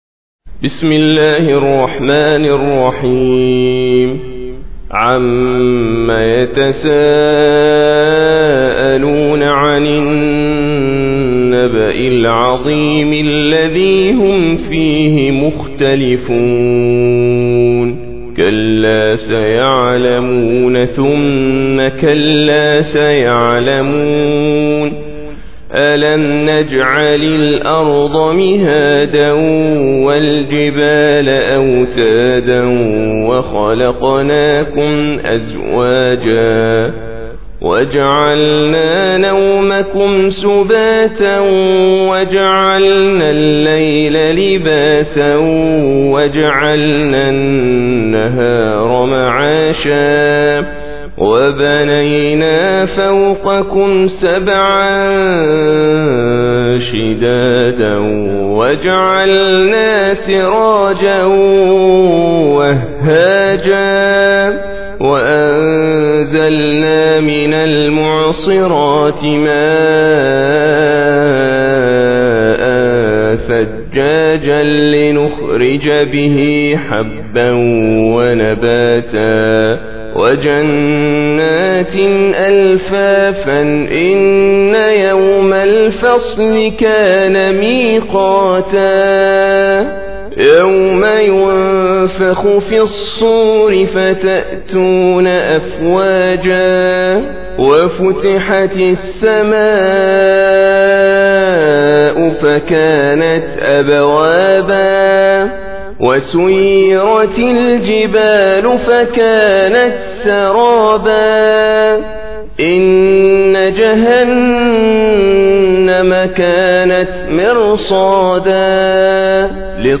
78. Surah An-Naba' سورة النبأ Audio Quran Tarteel Recitation
Surah Repeating تكرار السورة Download Surah حمّل السورة Reciting Murattalah Audio for 78. Surah An-Naba' سورة النبأ N.B *Surah Includes Al-Basmalah Reciters Sequents تتابع التلاوات Reciters Repeats تكرار التلاوات